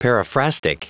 Prononciation du mot periphrastic en anglais (fichier audio)
Prononciation du mot : periphrastic
periphrastic.wav